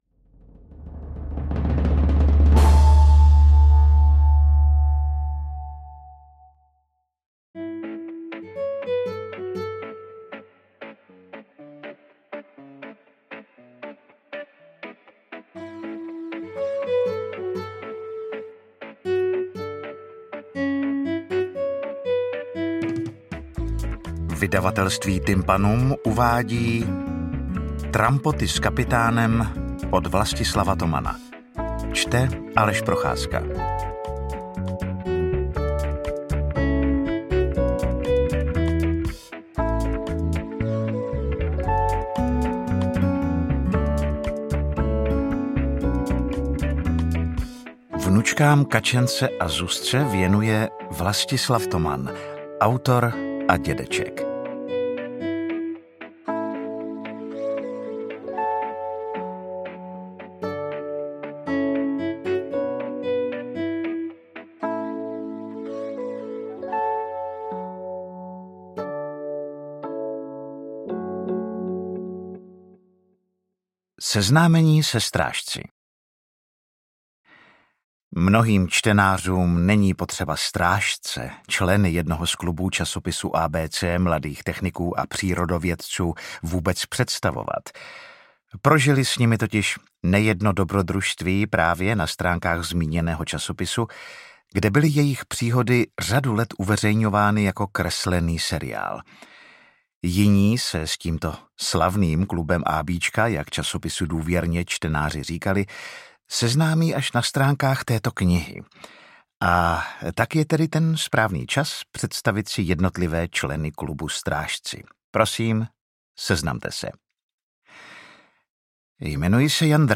AudioKniha ke stažení, 58 x mp3, délka 8 hod. 38 min., velikost 476,7 MB, česky